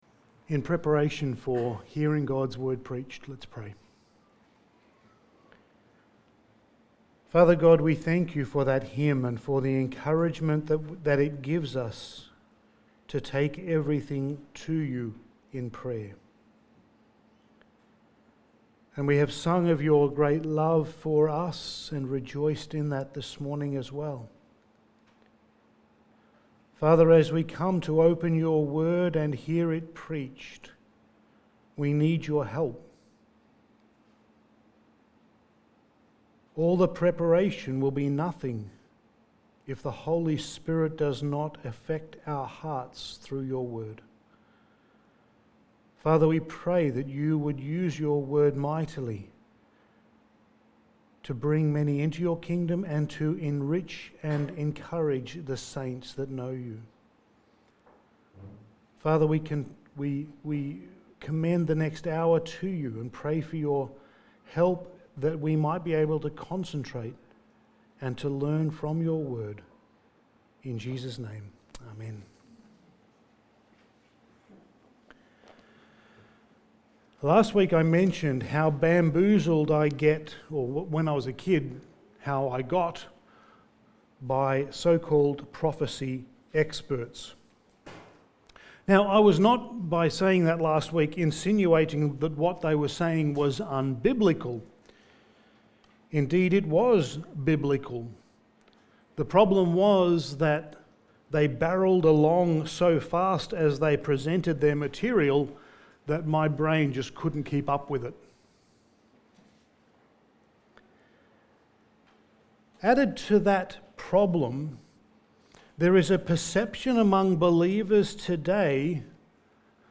Passage: Daniel 7:15-28 Service Type: Sunday Morning